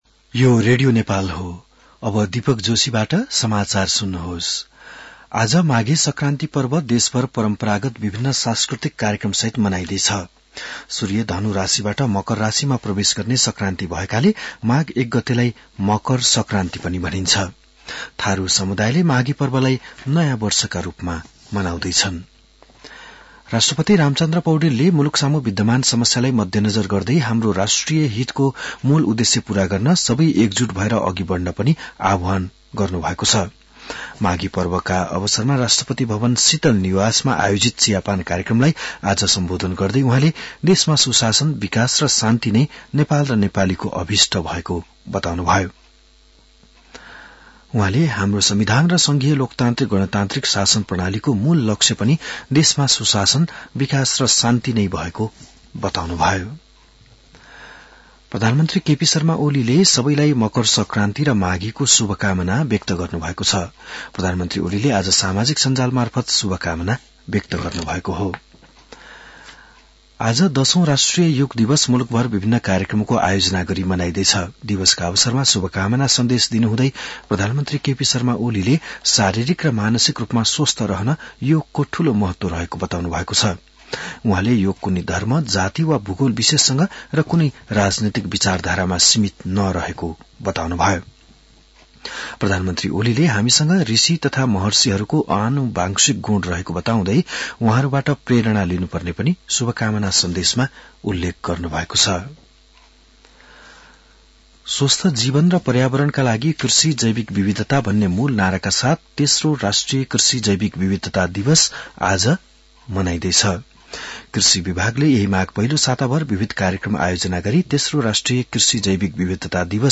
बिहान ११ बजेको नेपाली समाचार : २ माघ , २०८१
11-am-news-1.mp3